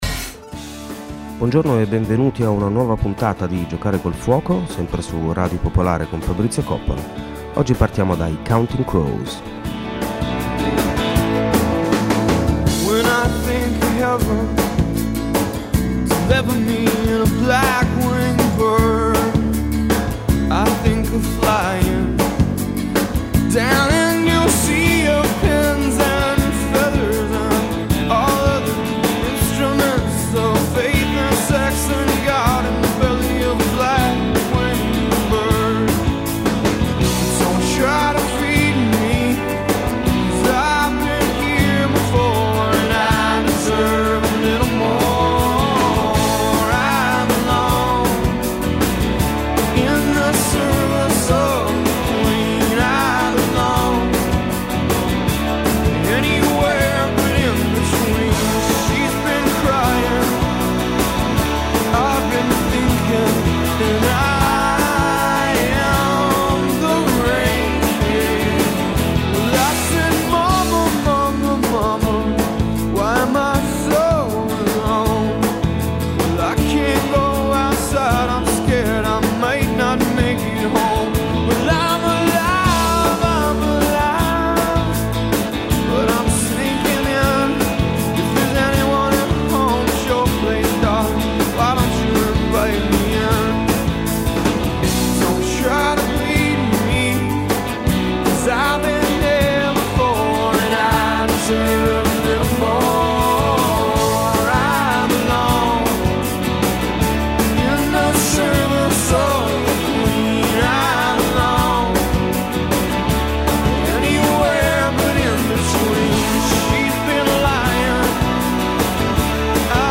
Ci muoveremo seguendo i percorsi segreti che legano le opere l’una all’altra, come a unire una serie di puntini immaginari su una mappa del tesoro. Memoir e saggi, fiction e non fiction, poesia (moltissima poesia), musica classica, folk, pop e r’n’r, mescolati insieme per provare a rimettere a fuoco la centralità dell’esperienza umana e del racconto che siamo in grado di farne.